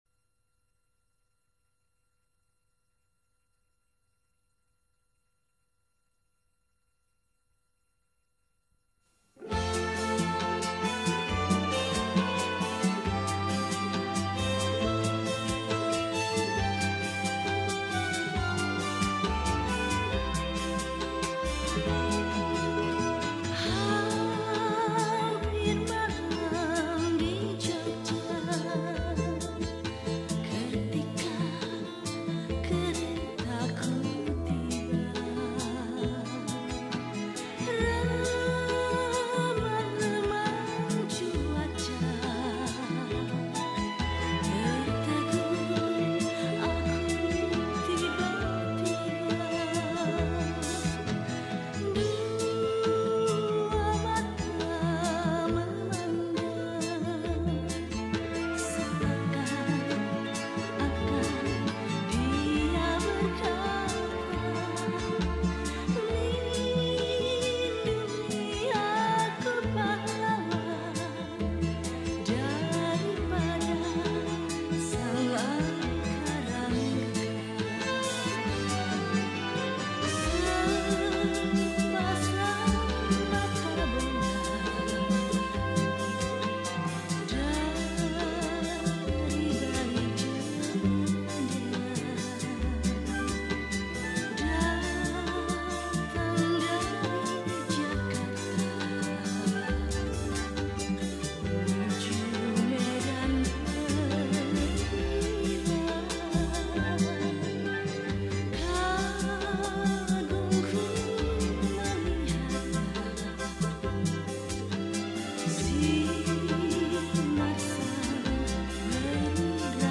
Indonesian Songs
Patriotic Songs